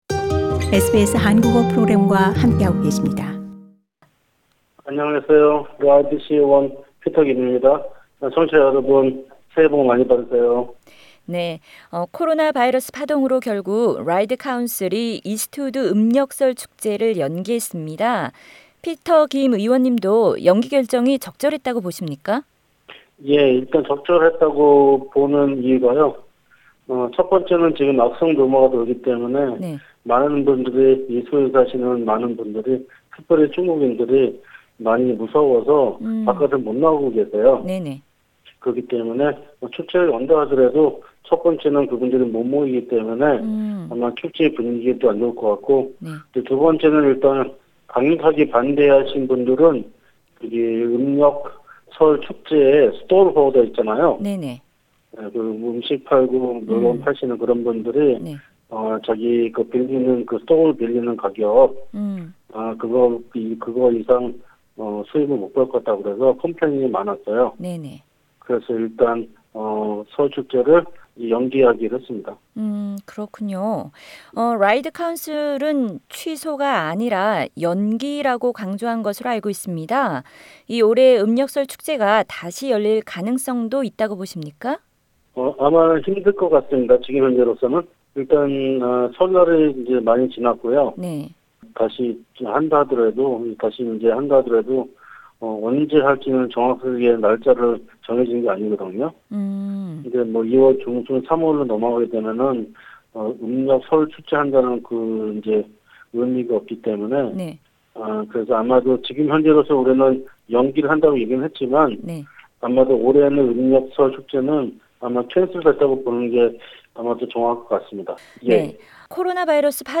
[피터 김 박사와의 인터뷰는 상단의 팟캐스트를 통해 접하시기 바랍니다.]